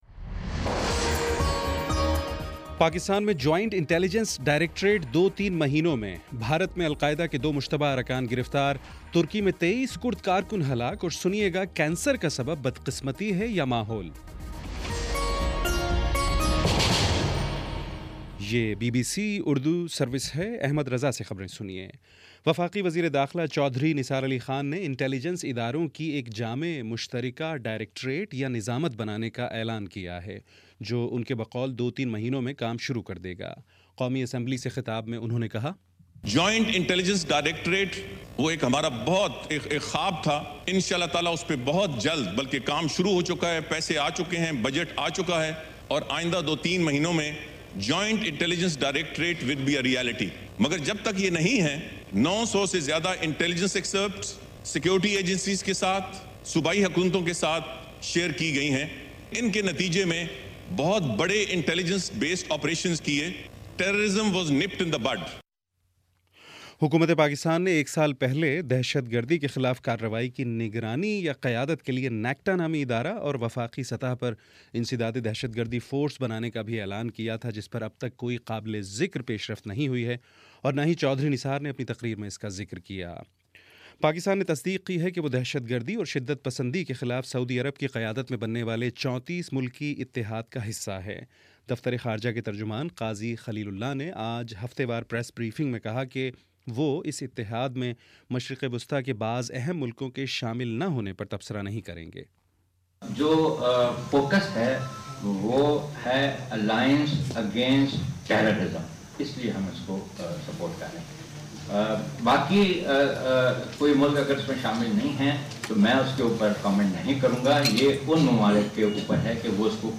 دسمبر 17 : شام پانچ بجے کا نیوز بُلیٹن